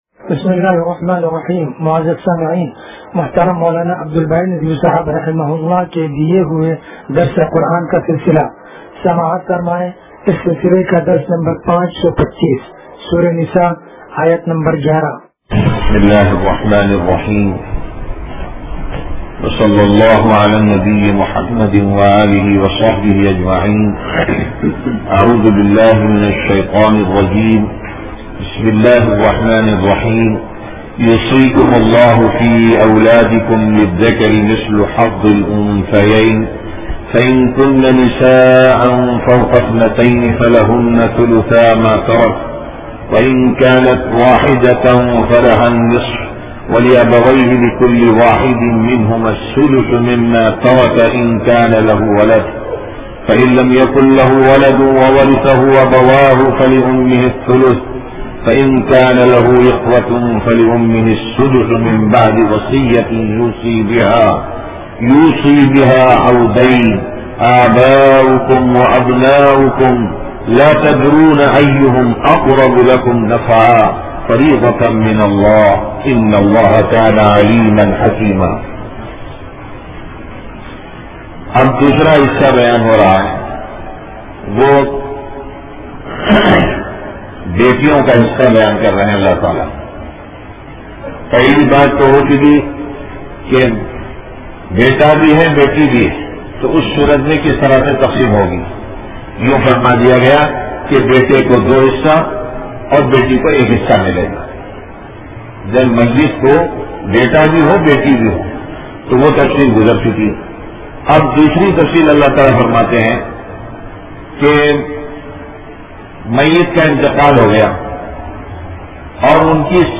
درس قرآن نمبر 0525